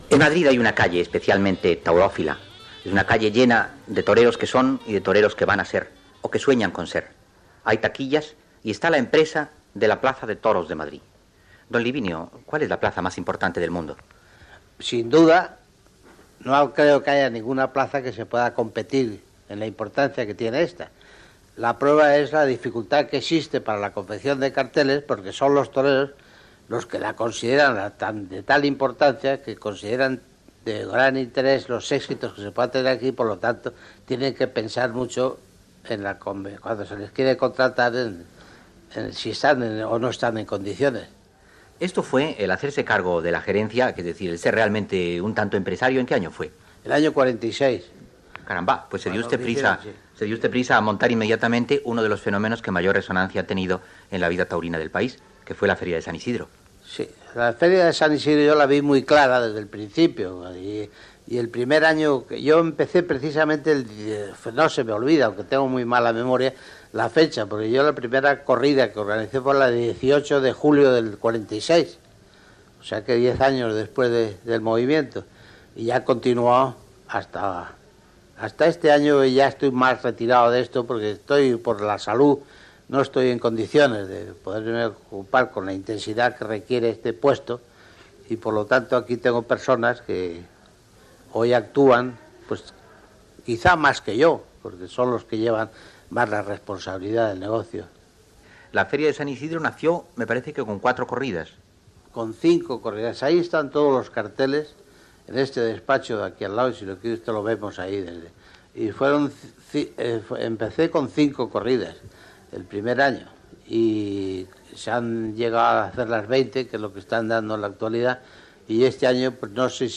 Entreteniment
Fragment extret del programa "Audios para recordar" de Radio 5 emès el 18de maig del 2015.